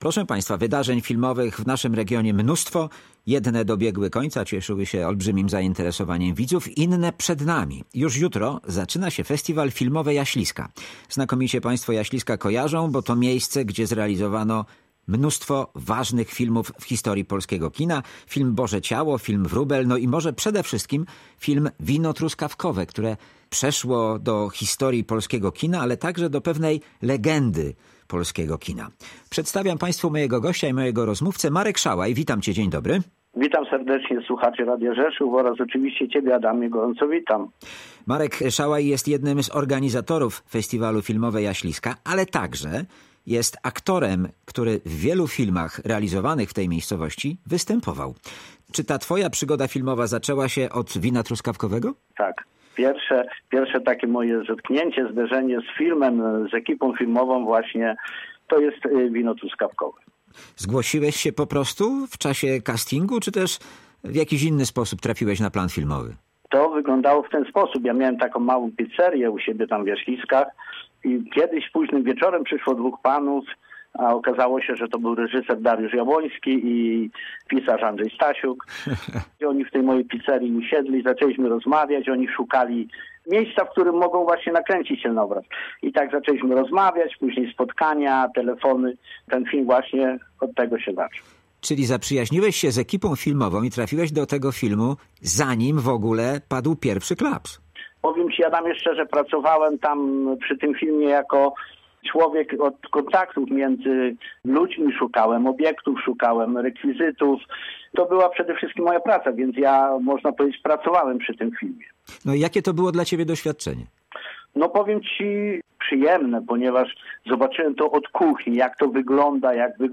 O tradycji realizacji filmów w Jaśliskach i programie festiwalu rozmawiają